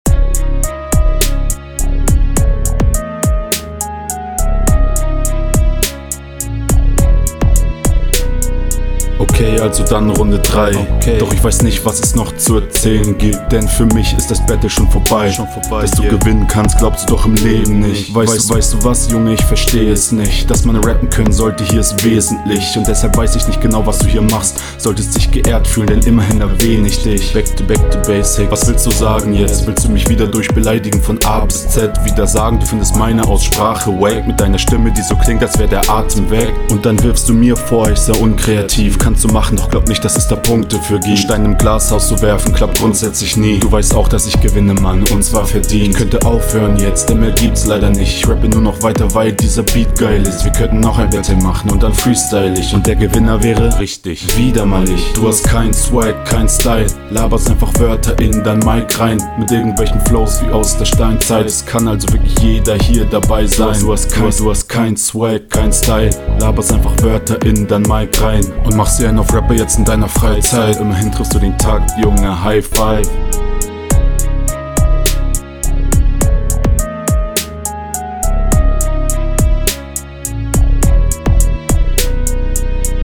Hier ist mische schlechter als in den runden zuvor, der beat ist echt zu leise.